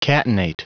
Prononciation du mot catenate en anglais (fichier audio)
Prononciation du mot : catenate